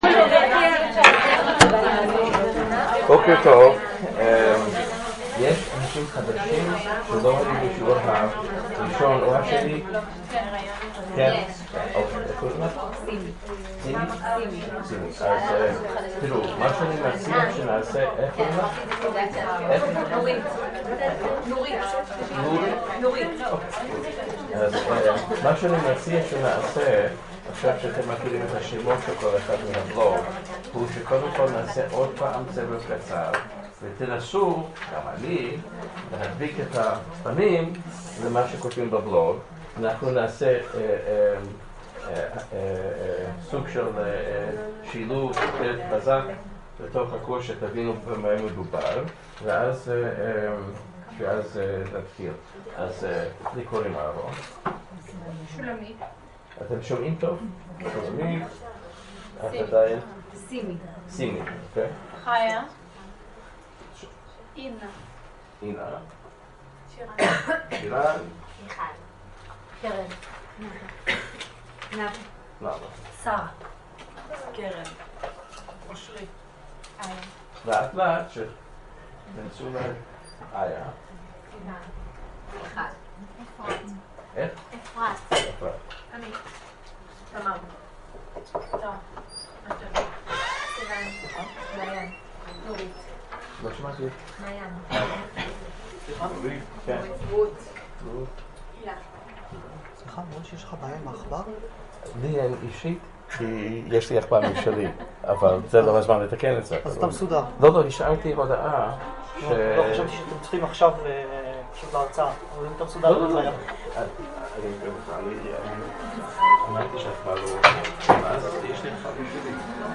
שיעור #3